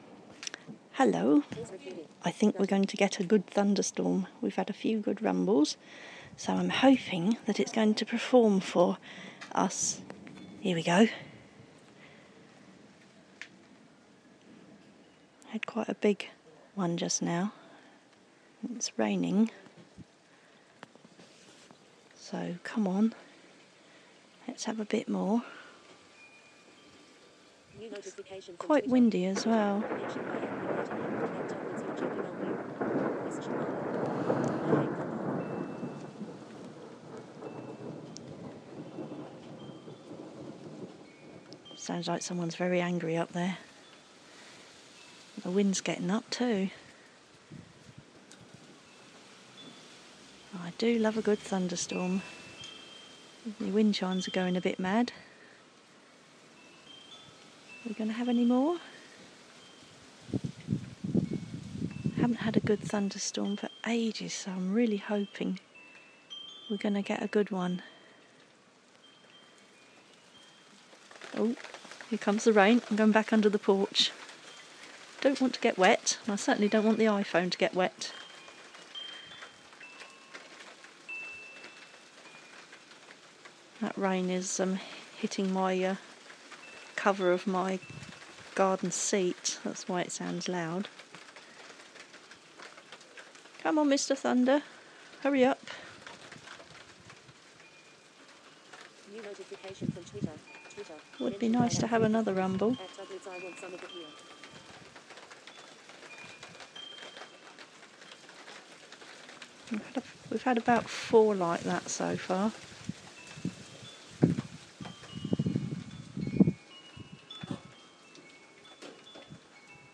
Thunder at last